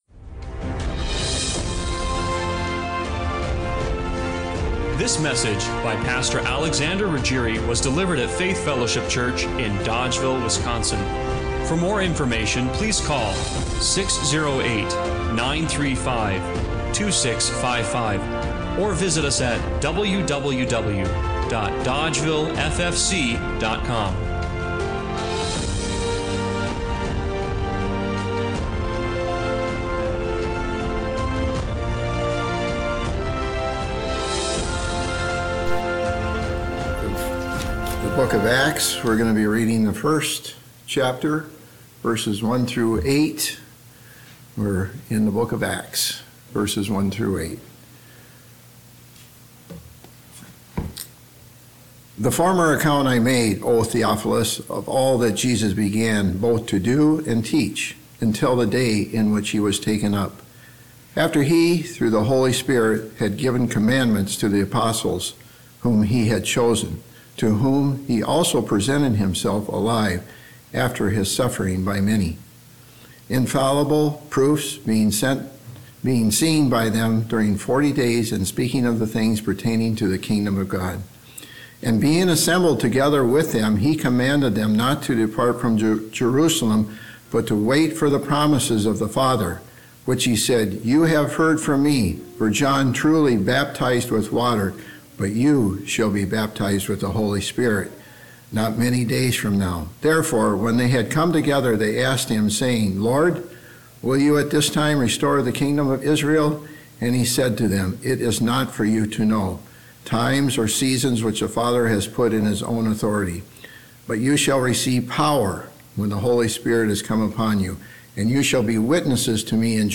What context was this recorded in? Acts 1:1-8 Service Type: Sunday Morning Worship What if the power that launched the early church is still available to you today?